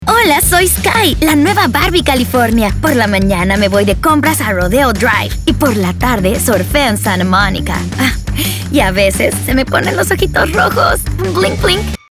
Commercial
Commercial voice over is all about connection. Adapting tone, pace, and style to authentically reflect each brand’s message and speak directly to its audience.